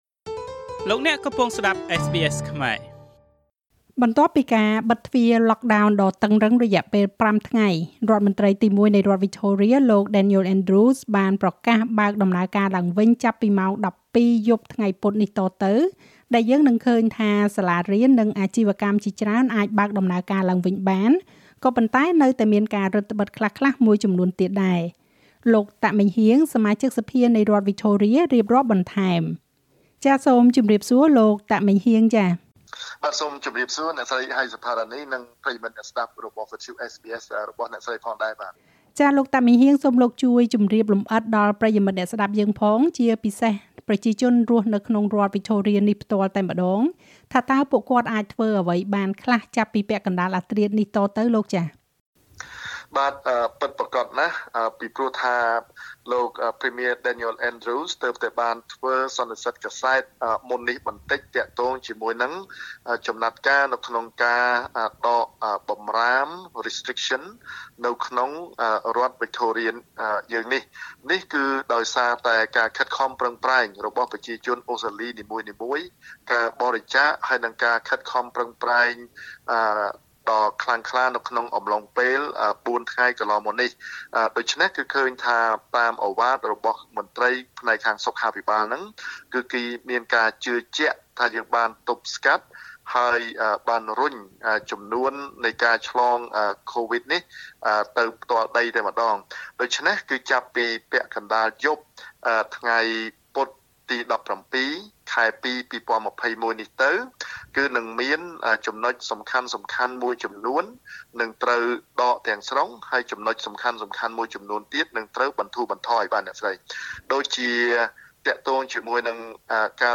បន្ទាប់ពីការបិទទ្វារ lockdown ដ៏តឹងរឹងរយៈពេល៥ថ្ងៃ រដ្ឋមន្រ្តីទីមួយនៃរដ្ឋវិចថូរៀបានប្រកាសបើកដំណើរការឡើងវិញចាប់ពីម៉ោង១២យប់ថ្ងៃពុធនេះតទៅ ដែលយើងនឹងឃើញថា សាលារៀន និងអាជីវកម្មជាច្រើនអាចបើកដំណើរការឡើងវិញ ប៉ុន្តែក៏នៅមានការរឹតត្បឹតខ្លះៗមួយចំនួនទៀតដែរ។ លោក តាក ម៉េងហ៊ាង សមាជិកសភានៃរដ្ឋវិចថូរៀ រៀបរាប់បន្ថែម។